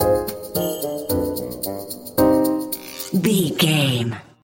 Aeolian/Minor
percussion
flute
orchestra
piano
silly
circus
goofy
comical
cheerful
perky
Light hearted
quirky